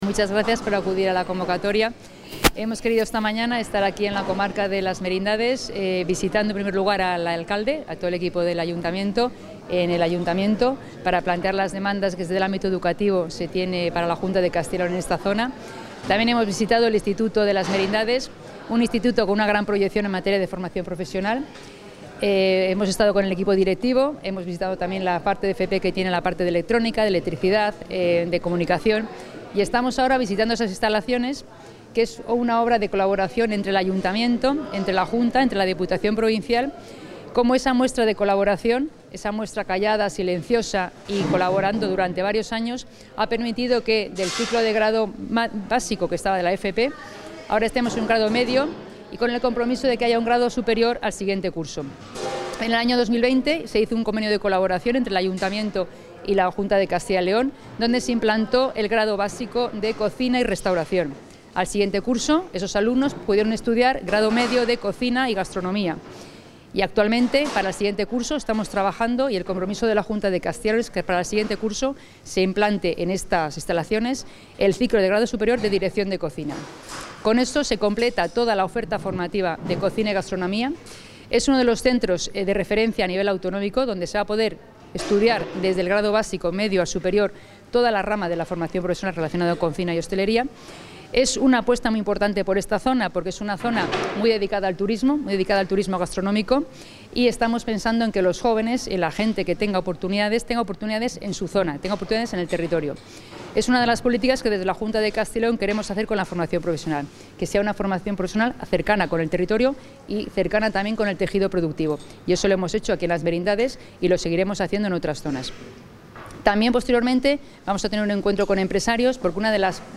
Declaraciones de la consejera.